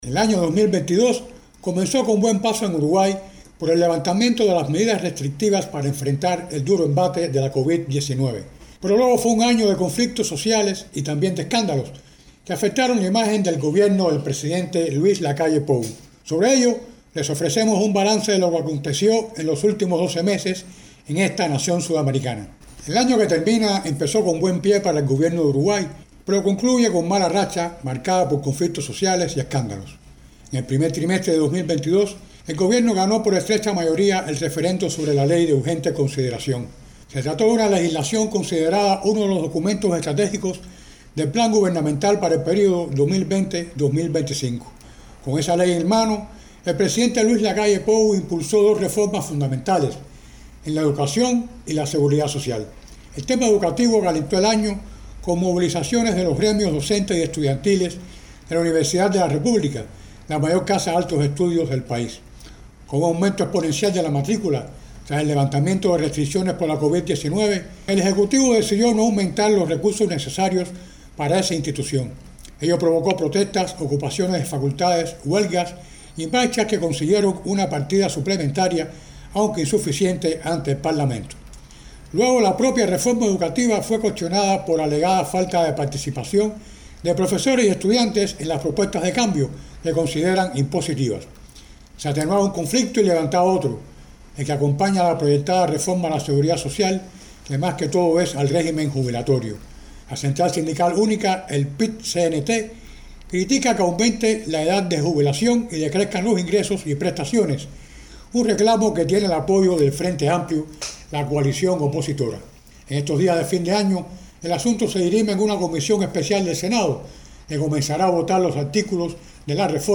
desde Montevideo